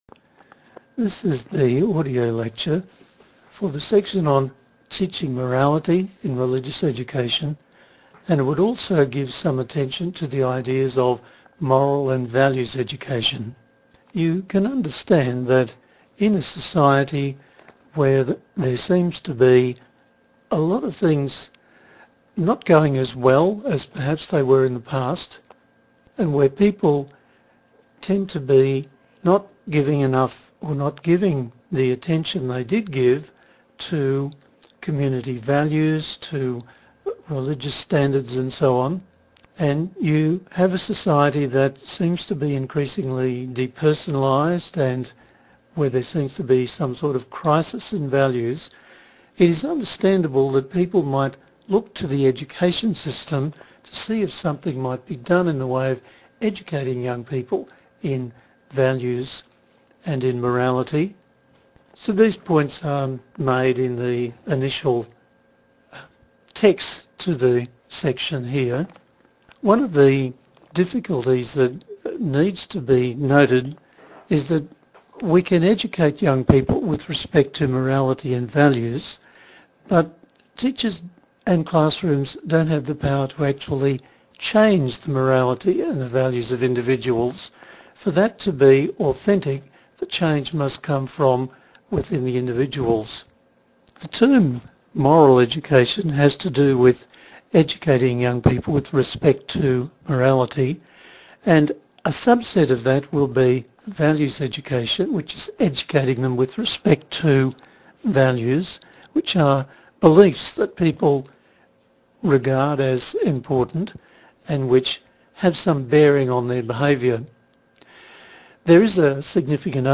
2. The audio file lecture Click the icon to hear or download the mp3 audio lecture file. This presents the content for this section -- the main presentation and principal source of information on the topic.